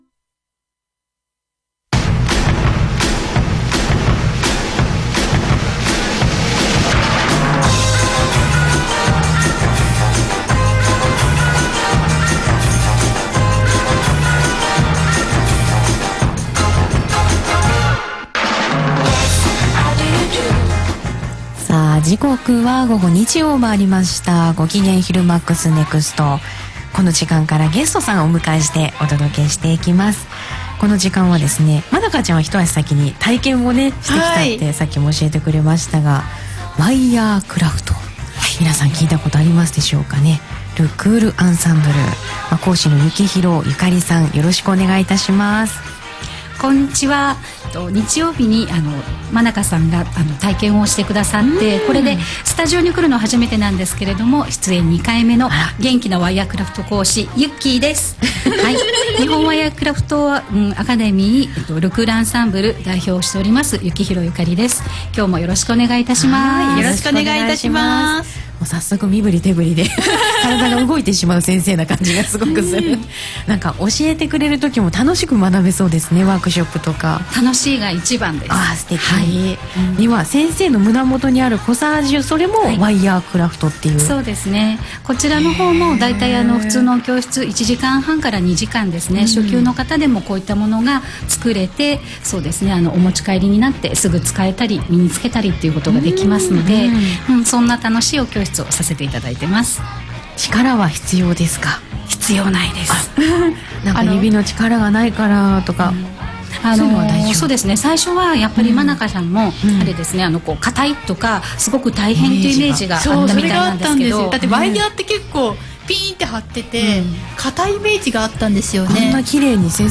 パソコンで録音しておきましたので、聞いてください。
とてもアマチュアとは思えない すばらし～い 声と言い 間の取り方 スピードなど 聞き取りやすく良い出来でした
スタジオで1番声が出てました、なかなかスタジオのマイク通りが合う声でした。